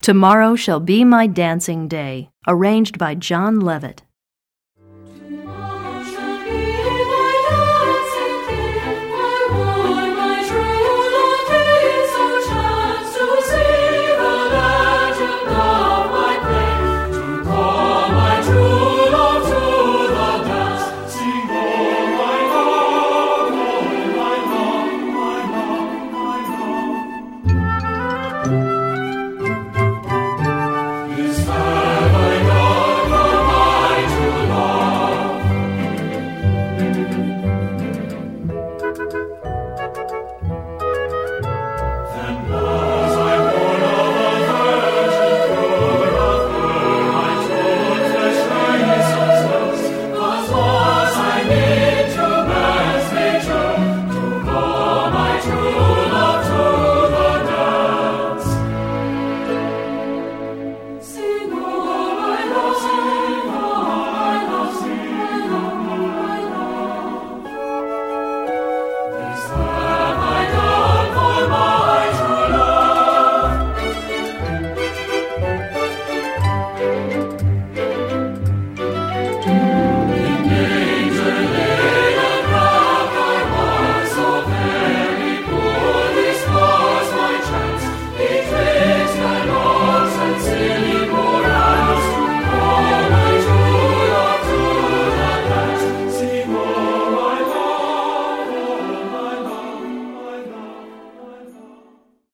Composer: Traditional English
Voicing: Accompaniment CD